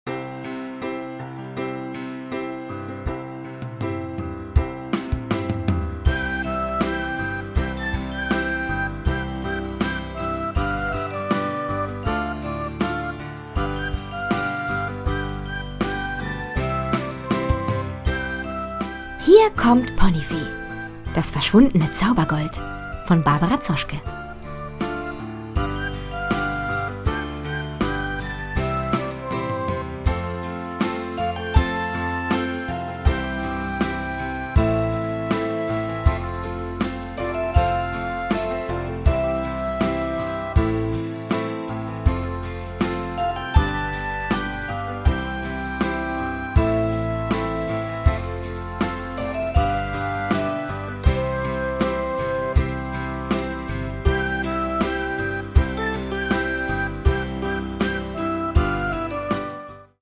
Produkttyp: Hörbuch-Download
Fassung: ungekürzte Fassung